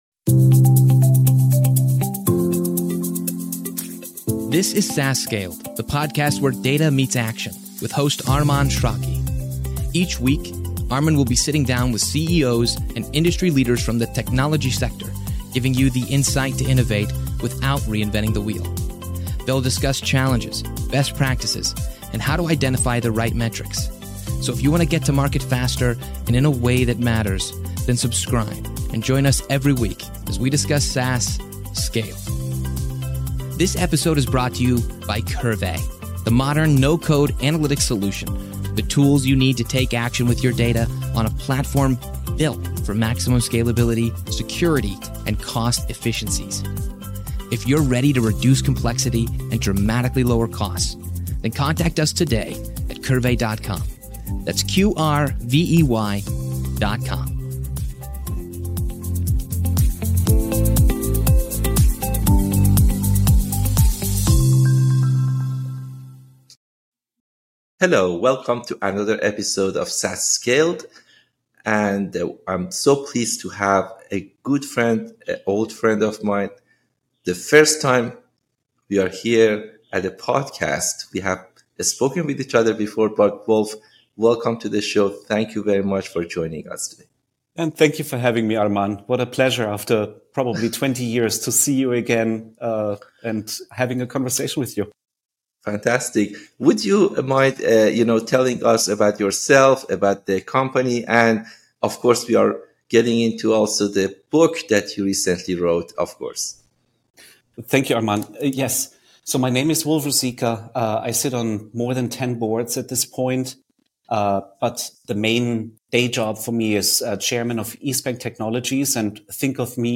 SaaS Scaled - Interviews about SaaS Startups, Analytics, & Operations